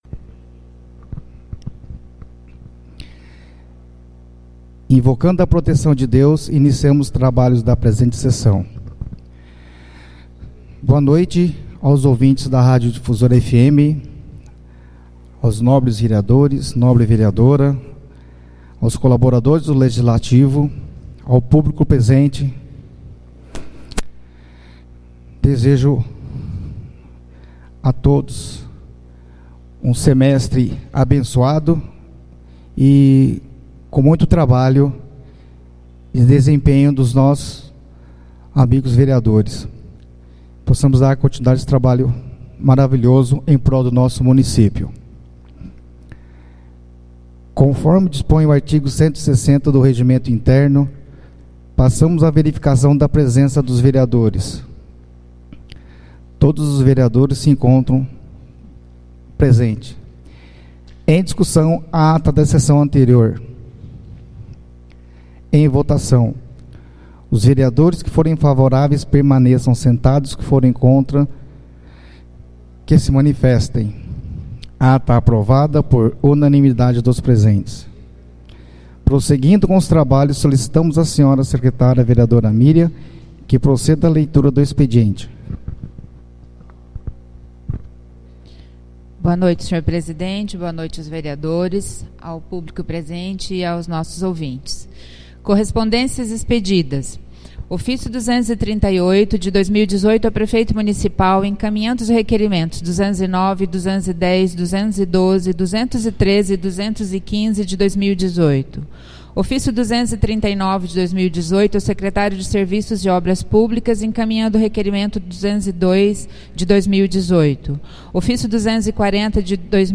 Áudio Sessões